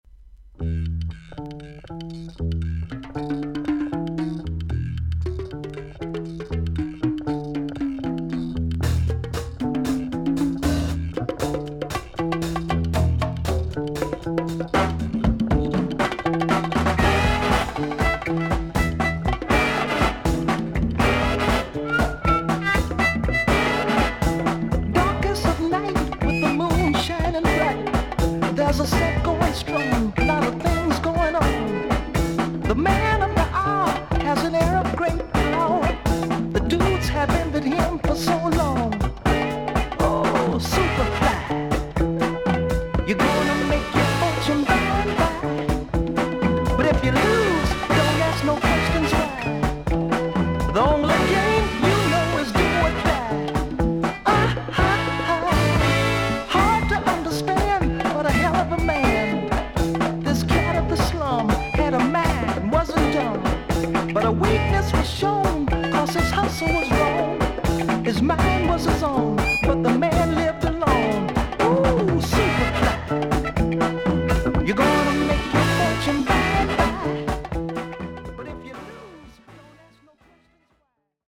ソウル・シンガー/ソング・ライター/ギタリスト。
VG++〜VG+ 少々軽いパチノイズの箇所あり。クリアな音です。